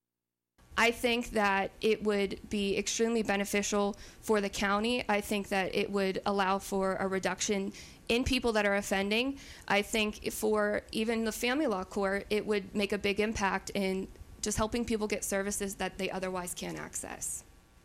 The three candidates for Indiana County Court of Common Pleas met at Renda Broadcasting and Digital’s Studios this morning to discuss several issues related to their position.